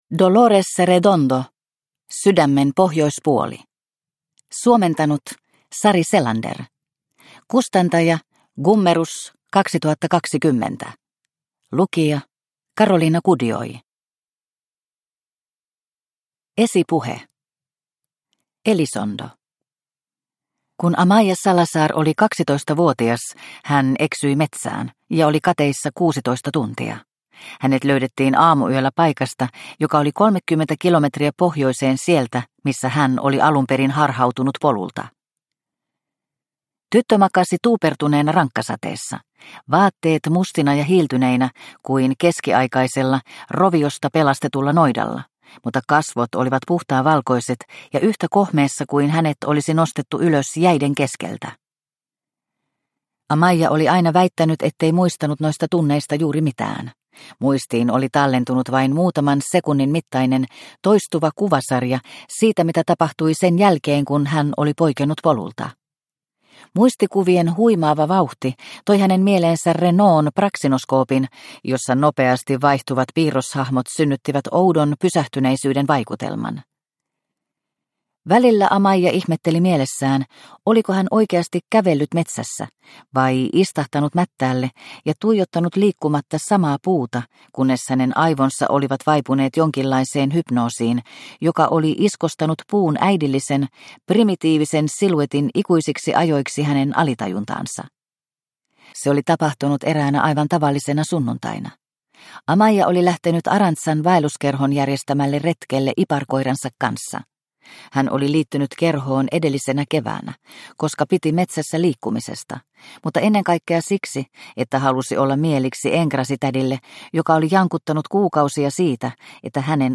Sydämen pohjoispuoli – Ljudbok – Laddas ner